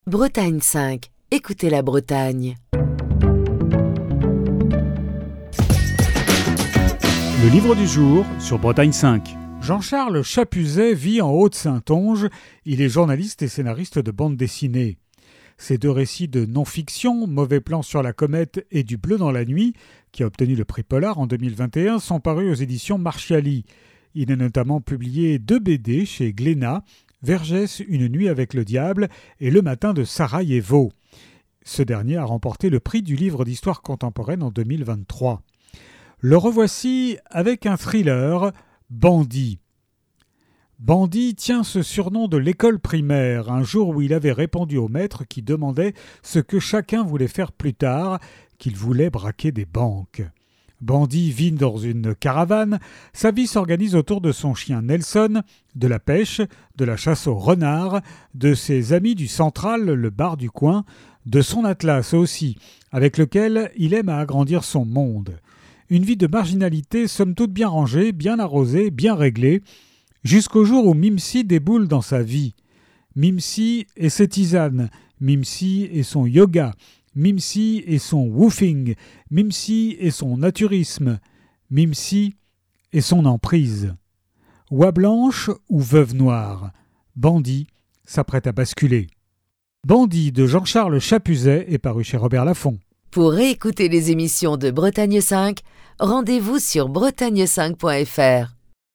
Chronique du 11 juin 2024.